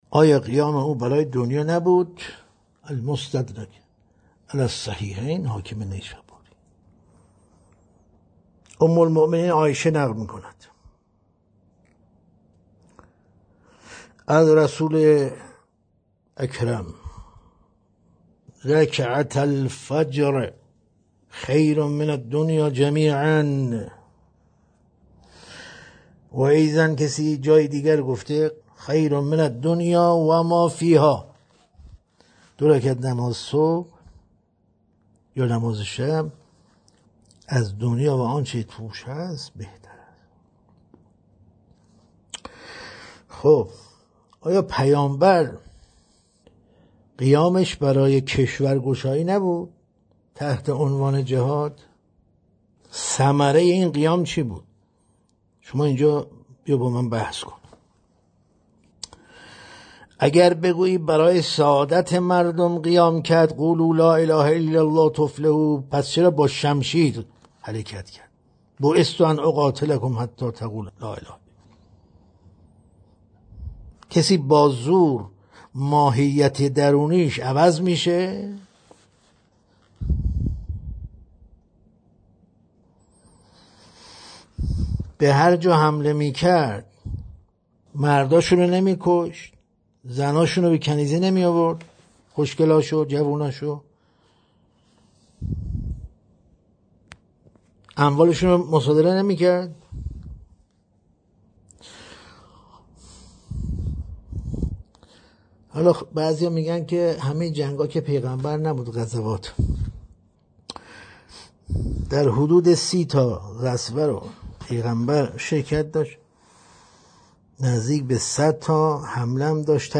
در این بخش، می‌توانید گزیده‌ای از تدریس‌های روزانه بروجردی، کاشف توحید بدون مرز، را مطالعه کرده و فایل صوتی آن را بشنوید.